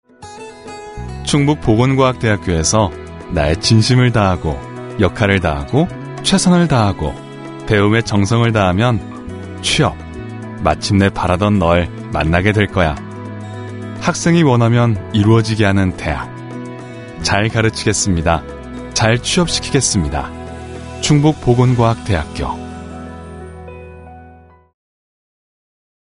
Native speaker Male 30-50 lat
Demo lektorskie